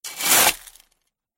Воткнули лопату в рыхлый песок